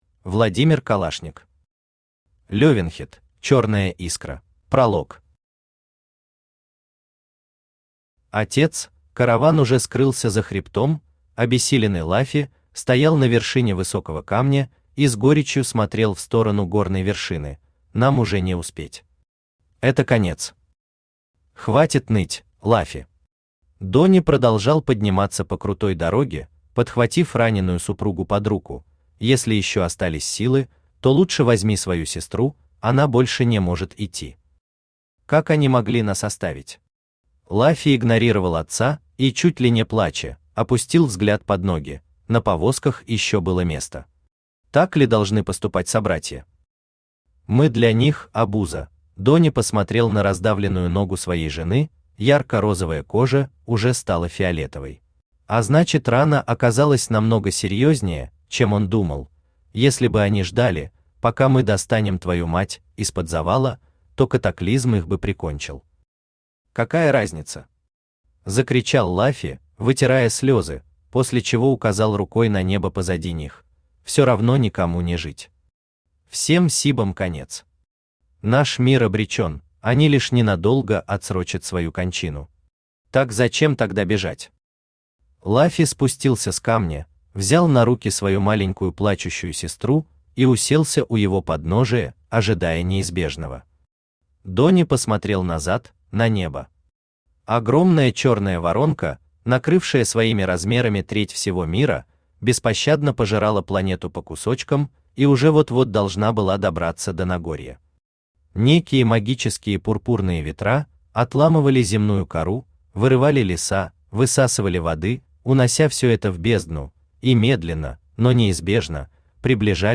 Аудиокнига Левенхет: Чёрная искра | Библиотека аудиокниг
Aудиокнига Левенхет: Чёрная искра Автор Владимир Калашник Читает аудиокнигу Авточтец ЛитРес.